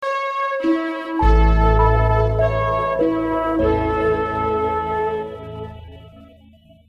posthorn_1.mp3